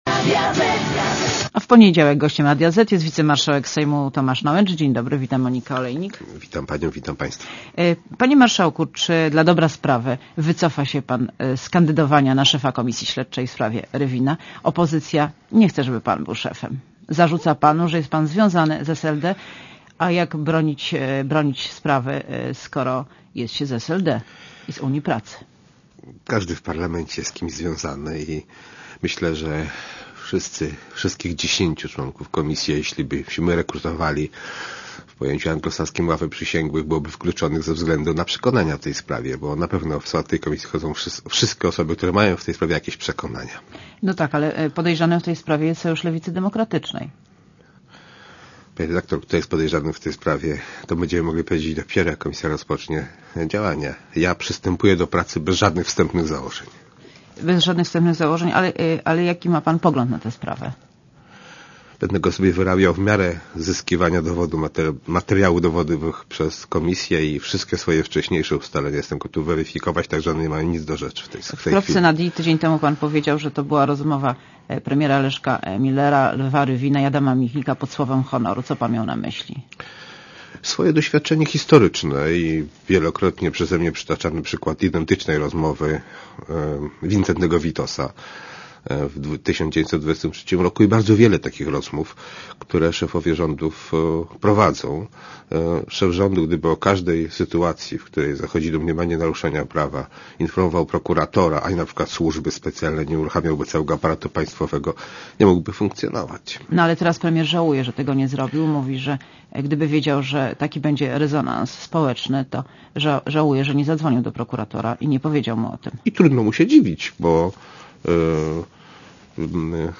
Monika Olejnik rozmawia z wicemarszałkiem Sejmu Tomaszem Nałęczem